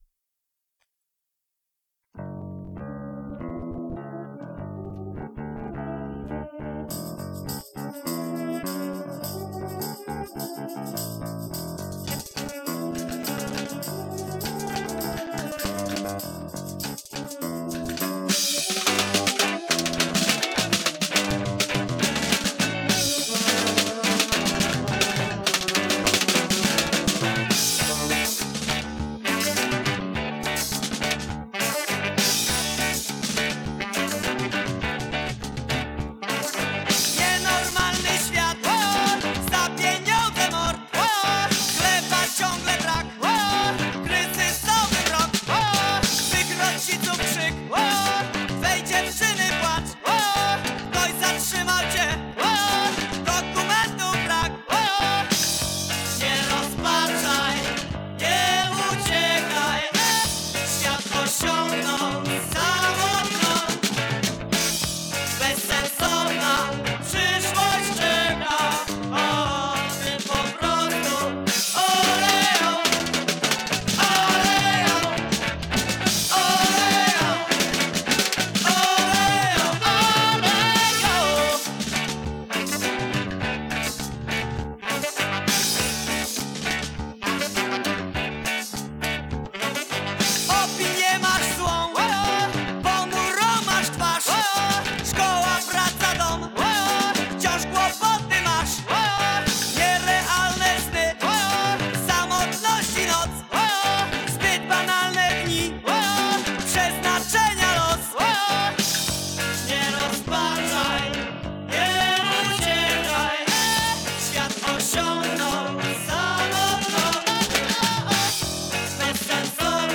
II Warszawski Festiwal Reggae.